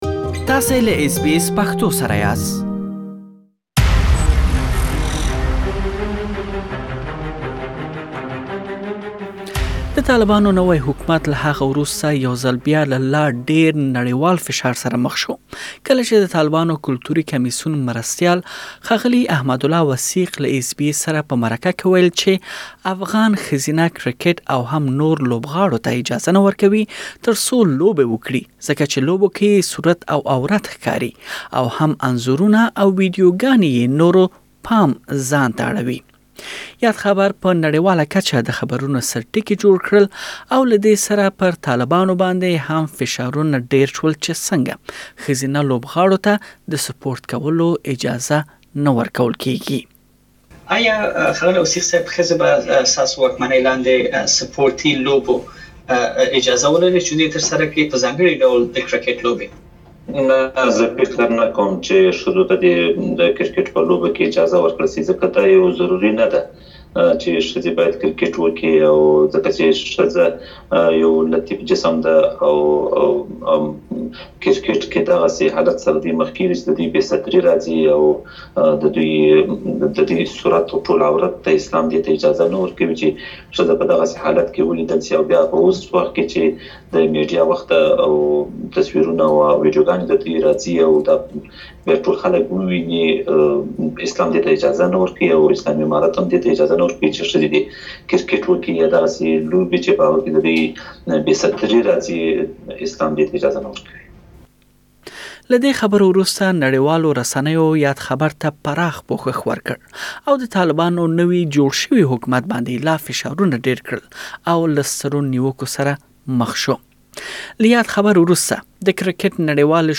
په ځانګړې مرکه کې